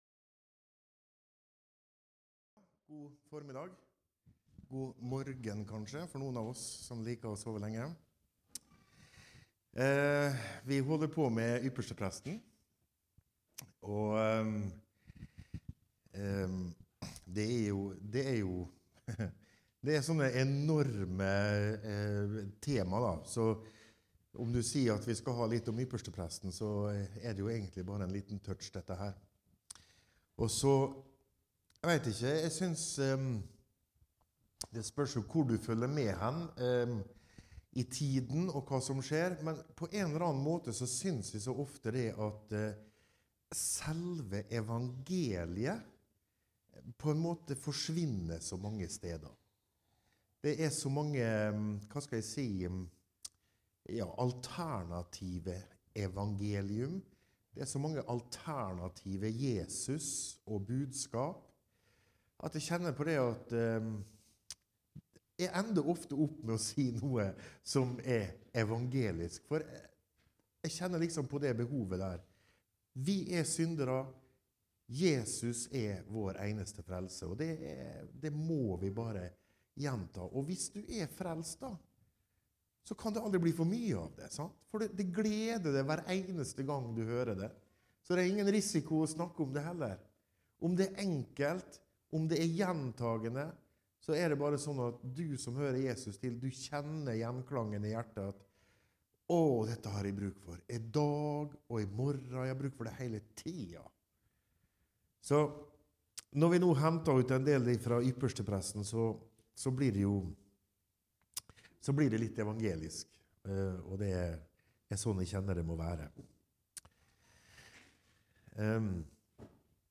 Fra møtehelg i November 2024 Tredje del dreier seg om Forhenget i tempelet